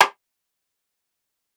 {Snr} 3000.wav